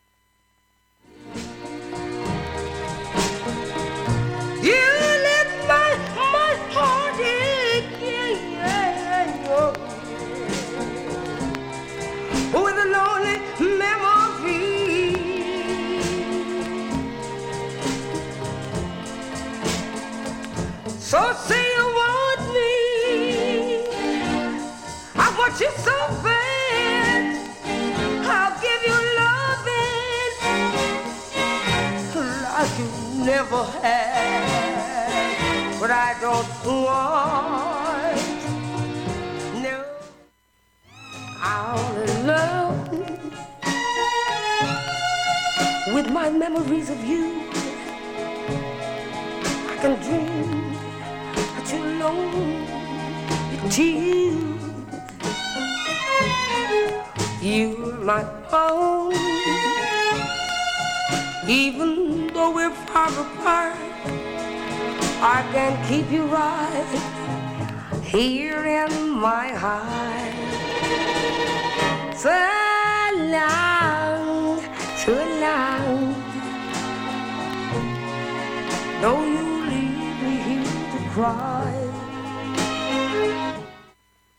結構クリアで音質良好全曲試聴済み。